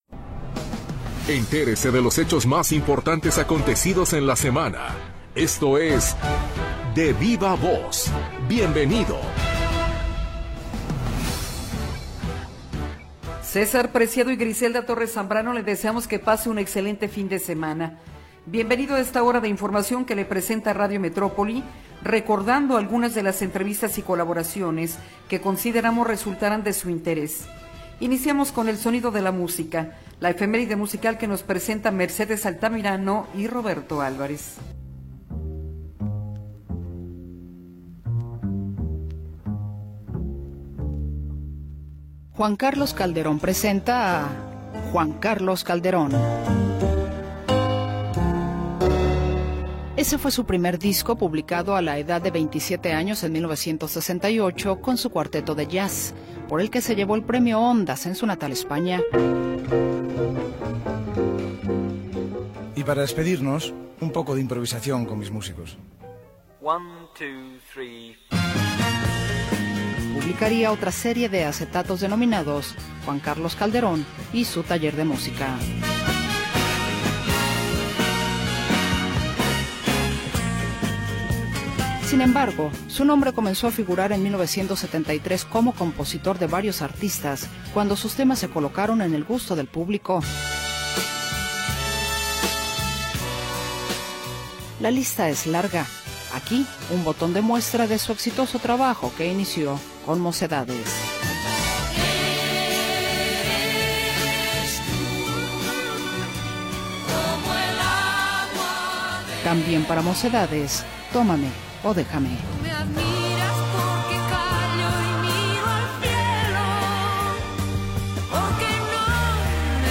Lo mejor de las entrevistas de la semana en Radio Metrópoli.